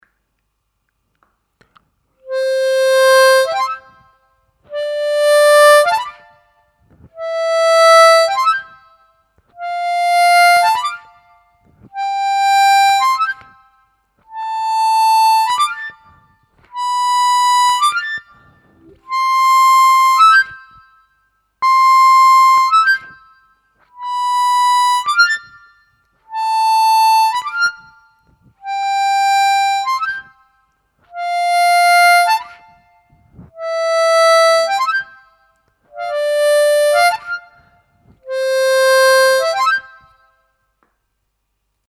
Слайд (глиссандо) и дроп-офф на губной гармошке
Сыграть гамму «до мажор» +4-4+5-5+6-6-7+7 и обратно +7-7-6+6-5+5-4+4, после каждой ноты играя дроп-офф (вверх).
gamma-dropoff-vverh.mp3